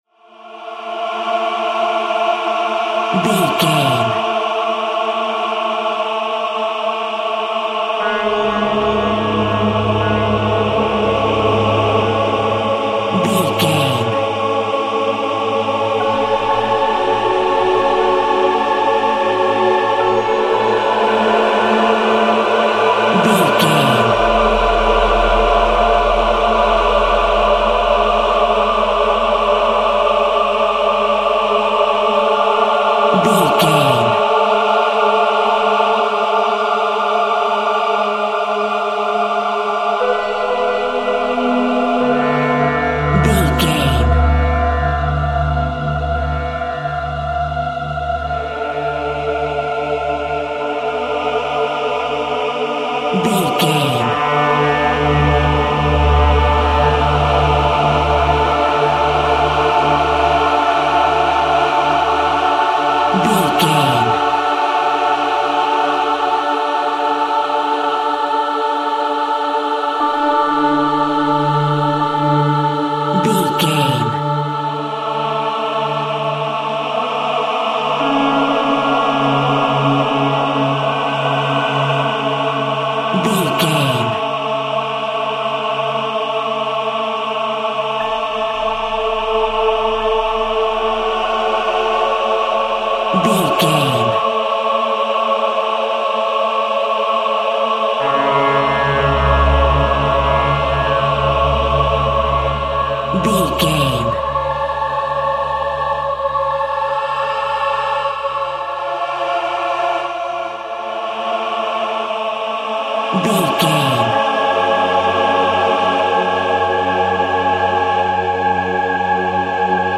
Horror Choir.
Atonal
scary
ominous
haunting
eerie
strings
vocals
piano
synth
pads